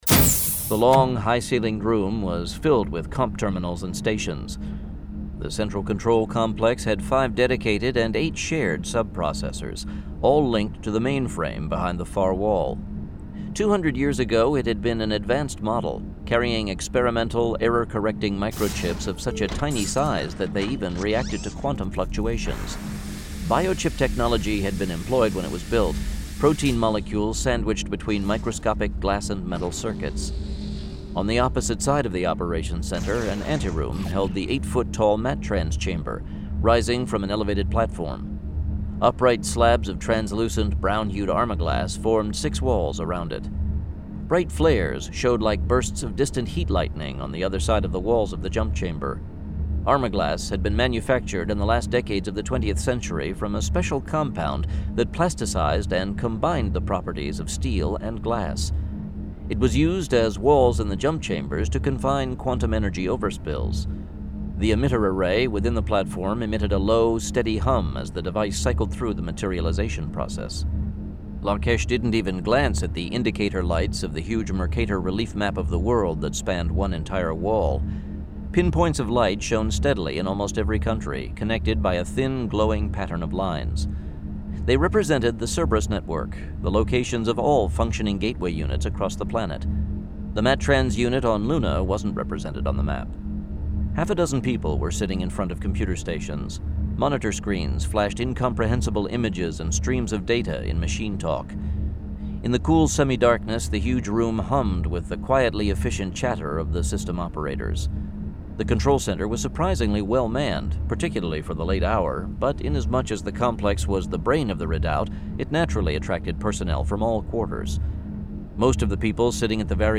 Outlanders 28: Mad God's Wrath [Dramatized Adaptation]